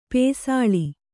♪ pēsāḷi